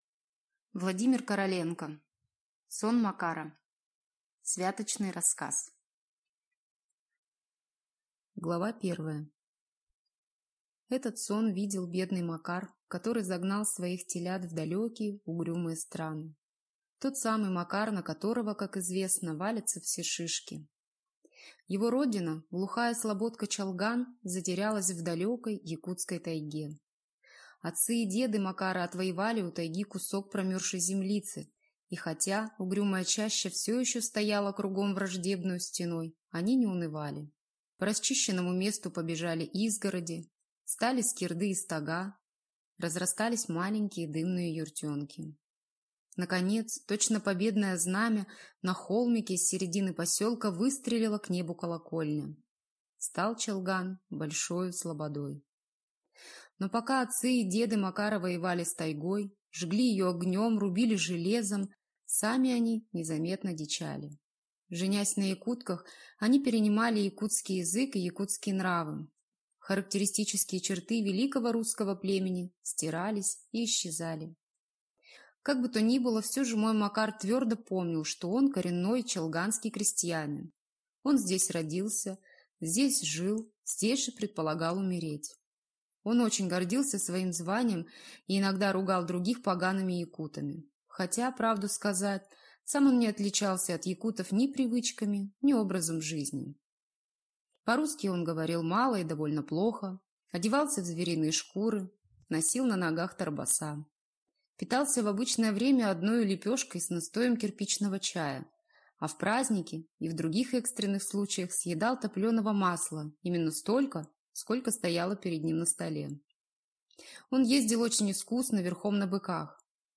Аудиокнига Сон Макара | Библиотека аудиокниг